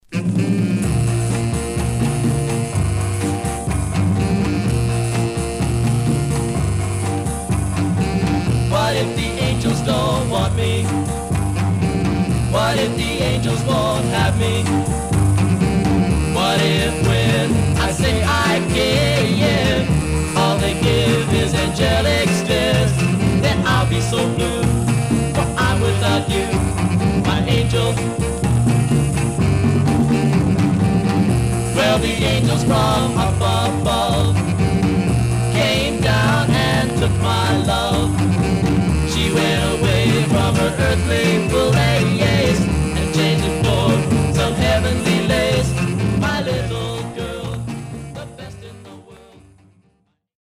Surface noise/wear Stereo/mono Mono
Teen